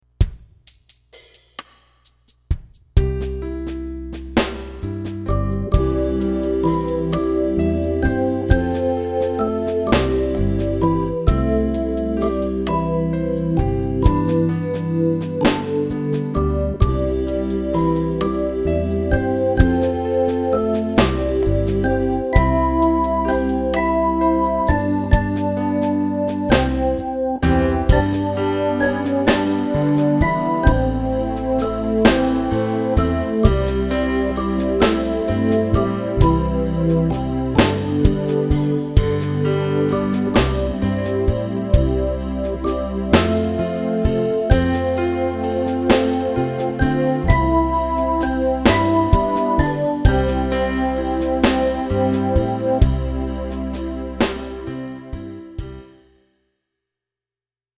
Métrica 8.7.8.7 con coro
Flauta (Tonada GATE AJAR) 1.